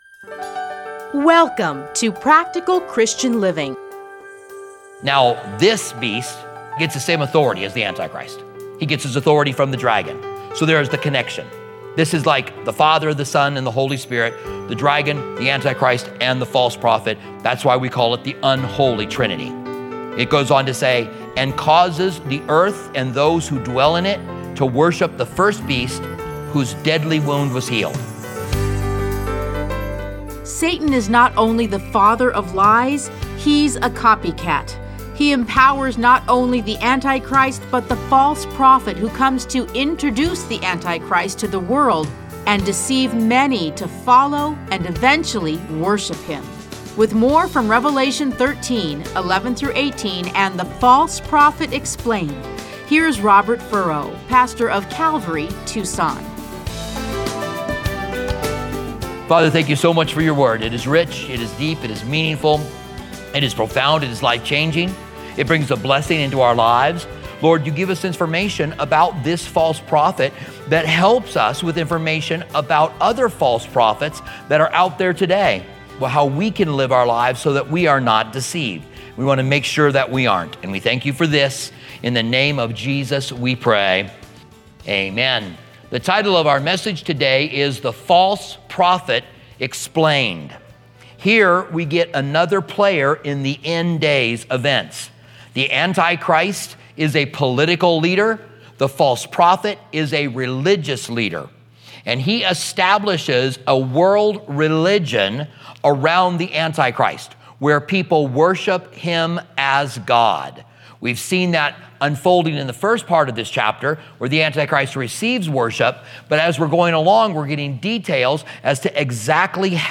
Listen to a teaching from Revelation 13:11-18.